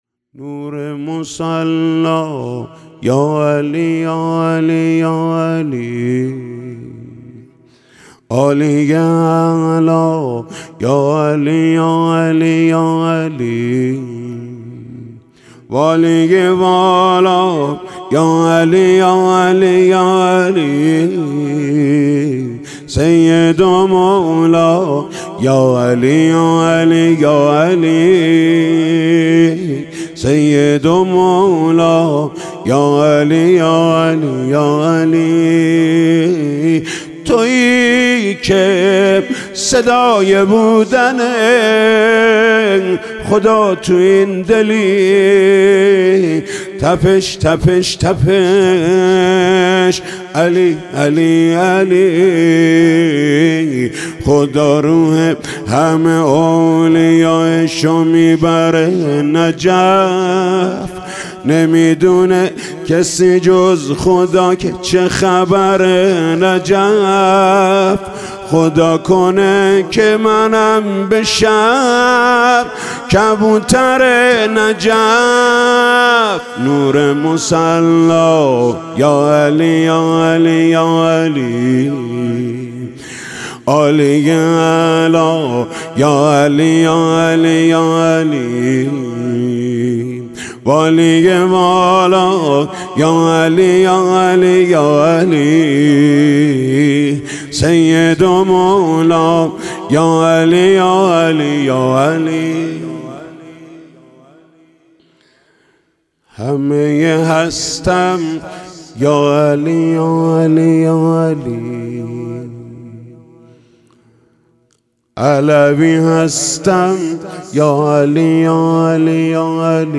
مراسم جشن میلاد امیرالمومنین (ع) شب گذشته شنبه ۱۷ اسفندماه که به دلیل شیوع ویروس کرونا در حسینیه مجازی هیأت رایةالعباس (ع) با مدیحه سرایی محمود کریمی برگزار شد.
در ادامه این مداح اهل بیت (ع) سرودی نیز با عنوان «نور مصلّى یاعلى» که بخشی از شعر آن به شرح زیر است: